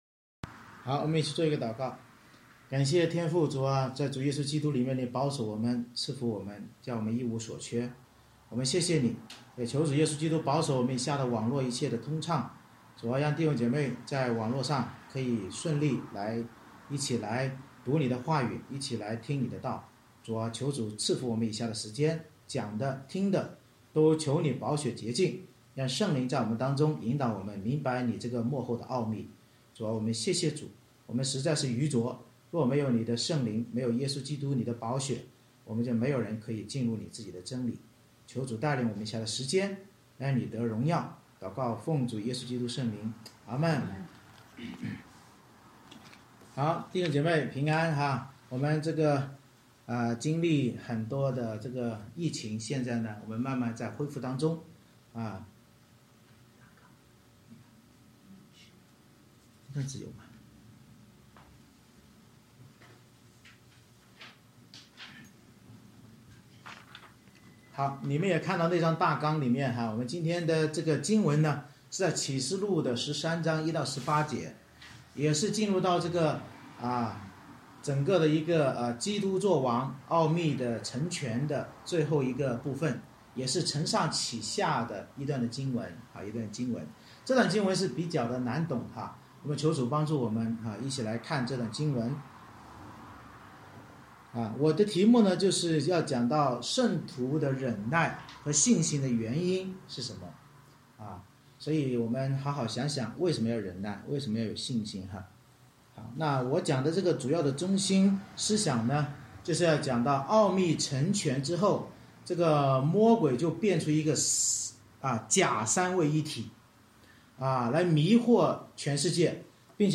July 25, 2021 圣徒忍耐和信心的原因 Series: 《启示录》讲道系列 Passage: 启示录13章 Service Type: 主日崇拜 奥秘成全后魔鬼假借三位一体迷惑全世界与基督教会争战，教导我们忍耐和信心原因是基督再来报应那些掳掠迫害教会圣徒的魔鬼及其跟随者。